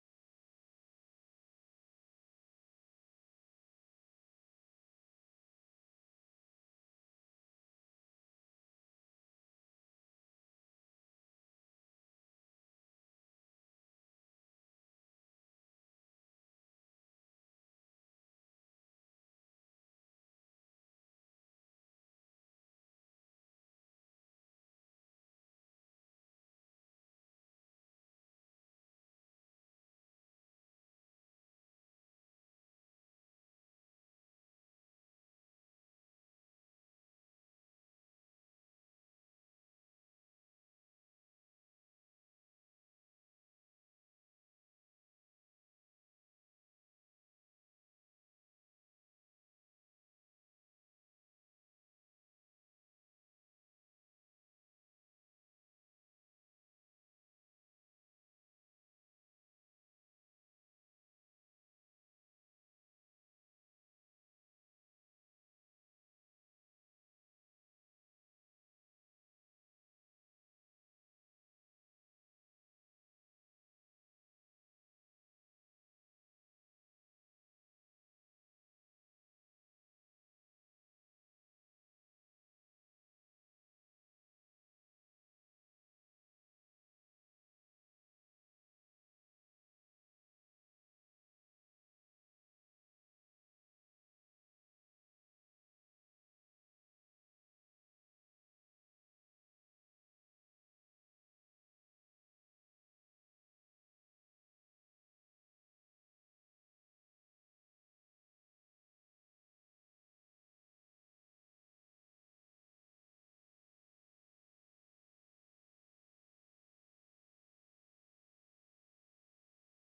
上記リンクからDLできるのは、年末からご提供させてもらってるオリジナル気功技術「大丈夫だよ」のヒーリングが発生する無音mp3です。